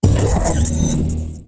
attack2.wav